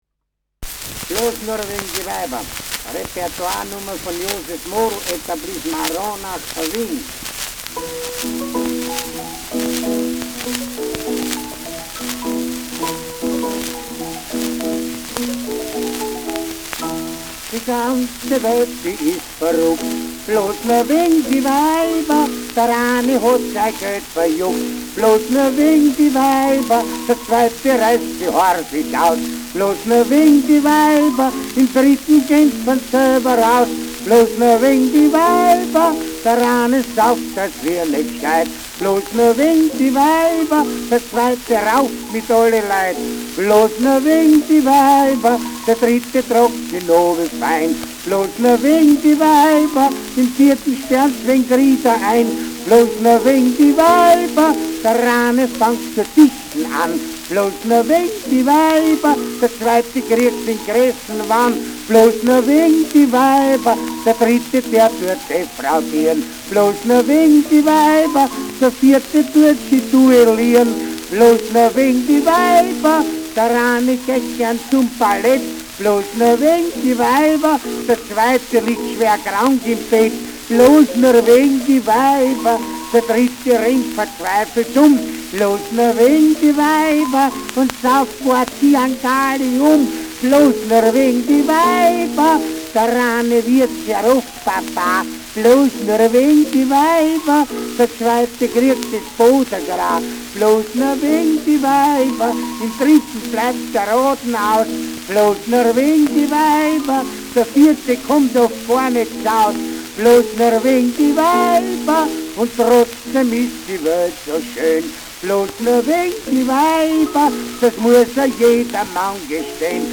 Schellackplatte
Nadelgeräusch : präsentes Rauschen : präsentes Knistern : abgespielt : vereinzeltes Knacken : leichtes Leiern
[unbekannte musikalische Begleitung] (Interpretation)
[Wien] (Aufnahmeort)